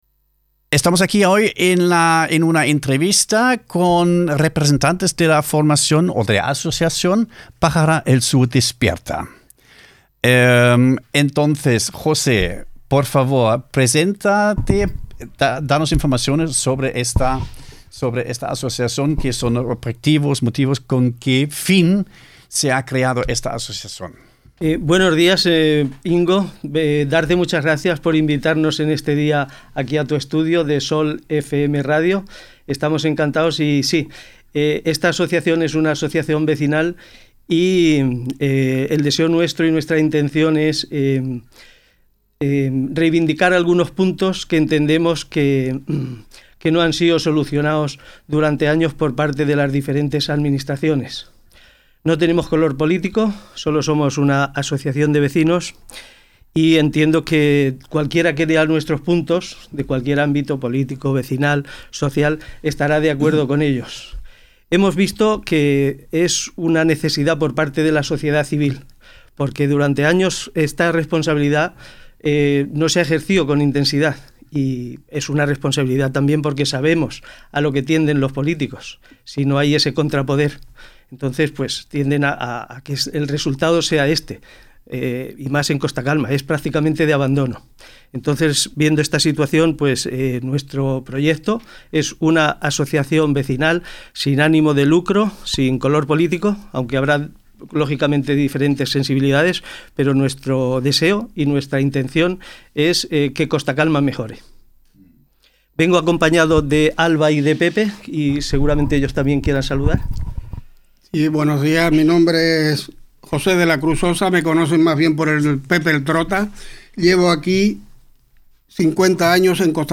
Costa Calma erhebt die Stimme – wenn ein Ort nicht länger schweigen will, Vertreter von Pajara El Sur Despierta waren im Interview bei Radio Sol FM